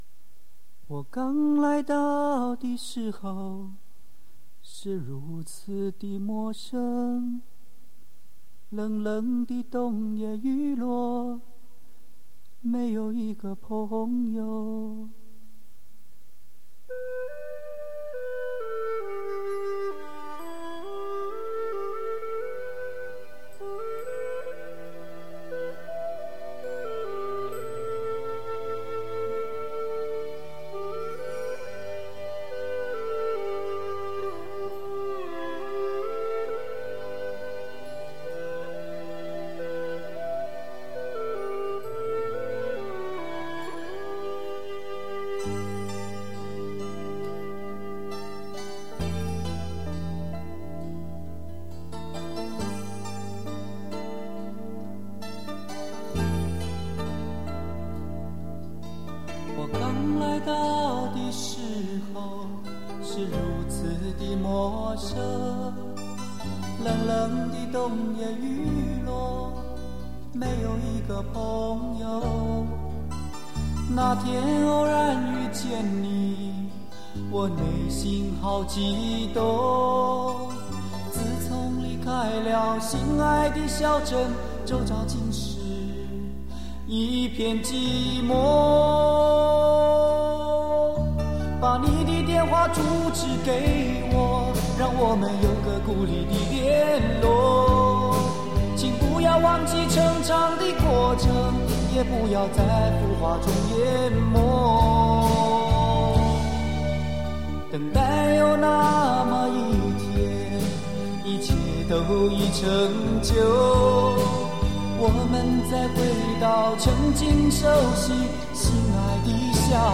磁带数字化